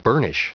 Prononciation du mot burnish en anglais (fichier audio)
Prononciation du mot : burnish